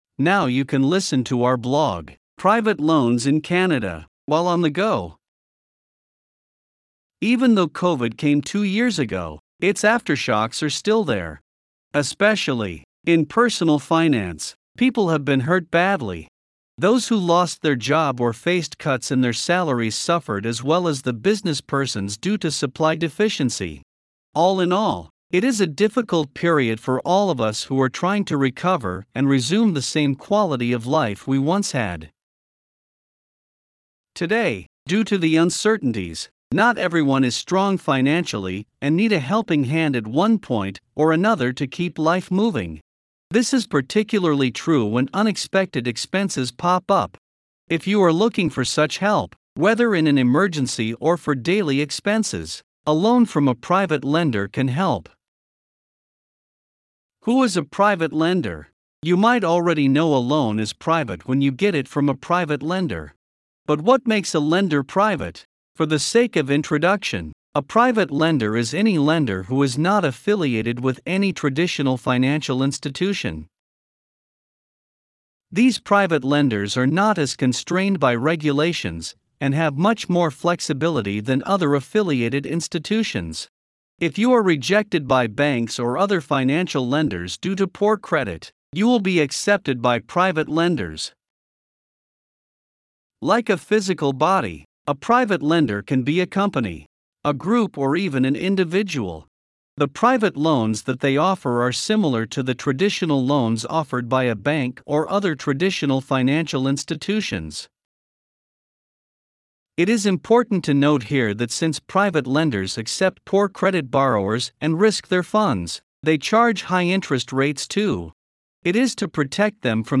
Now you can listen to our blog, “Private Loans in Canada”, while on the go.
Voiceovers-Voices-by-Listnr-2.mp3